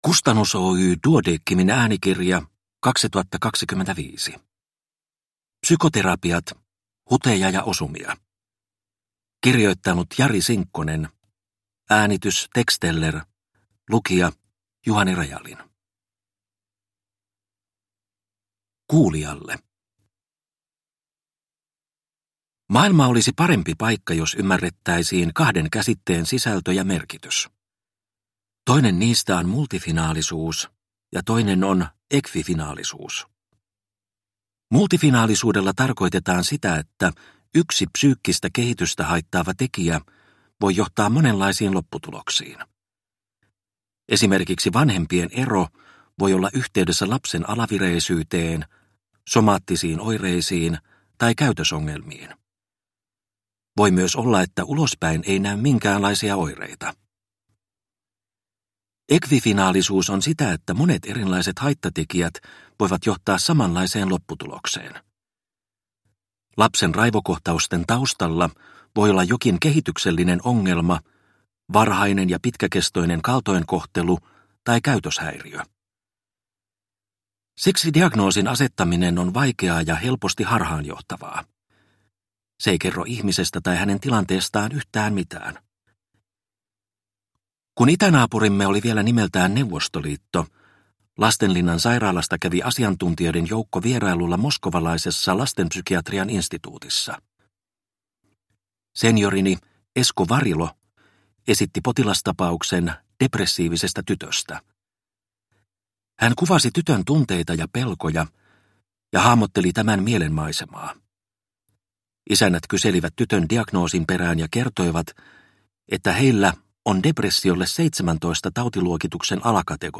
Psykoterapiat – Ljudbok